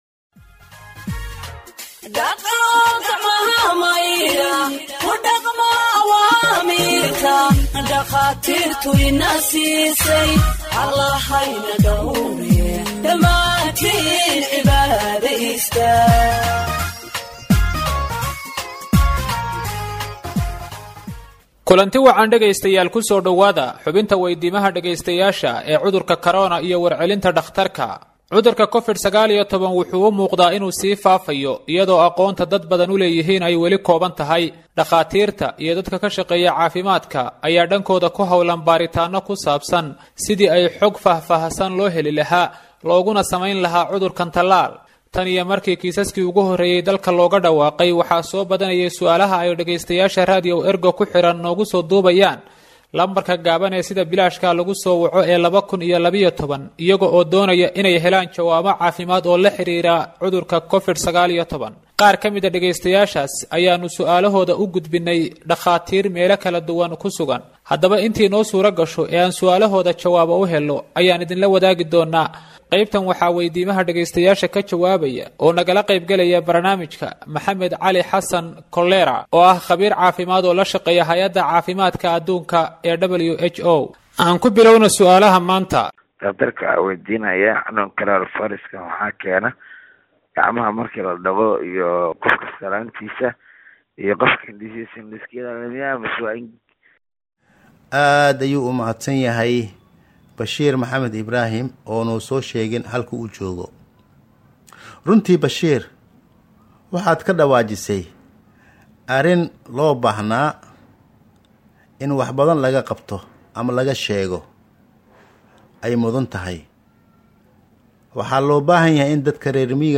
Health expert answers listeners’ questions on COVID 19 (22)